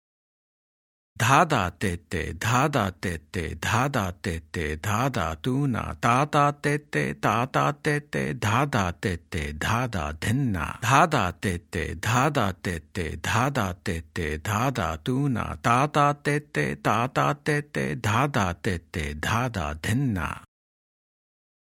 Example 1 (Dohra) – Spoken Twice